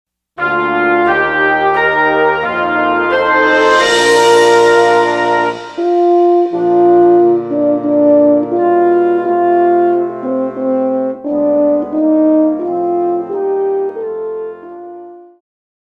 Classical
Band
Instrumental
Only backing